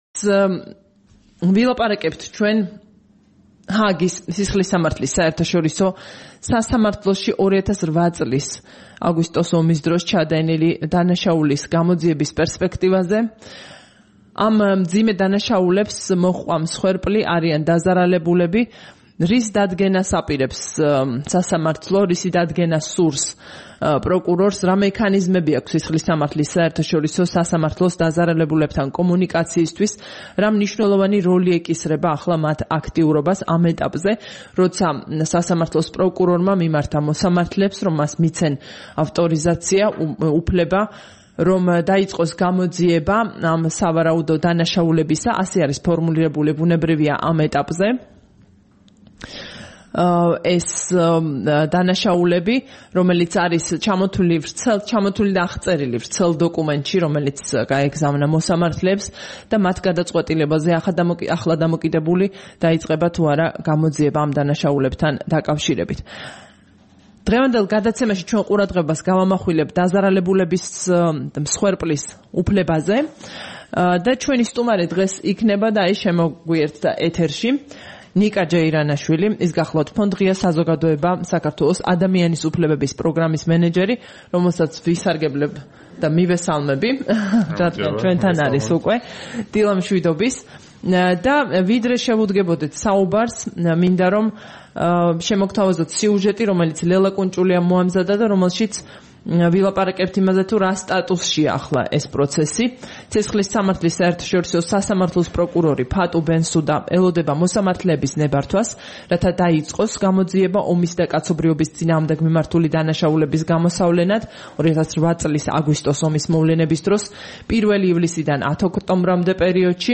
სტუმრად ჩვენს ეთერში
საუბარი